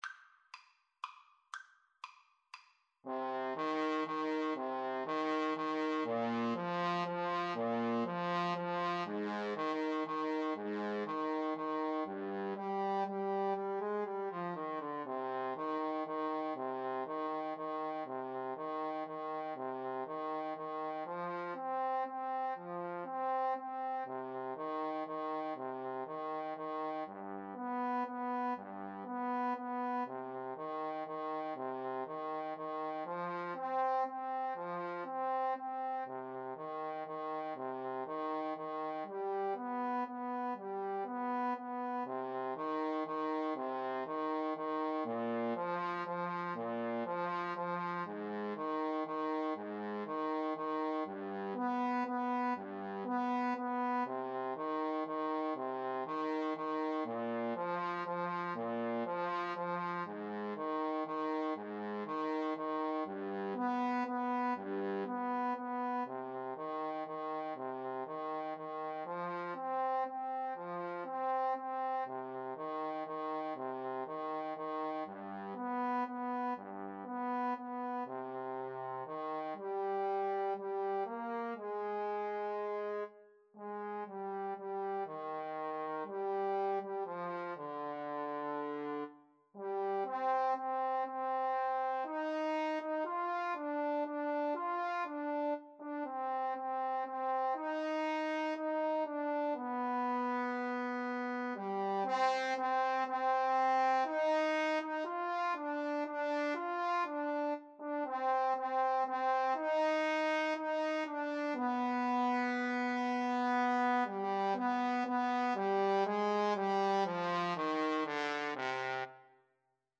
3/4 (View more 3/4 Music)
Slow Waltz .=40